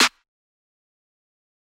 Astroworld SN.wav